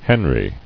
[hen·ry]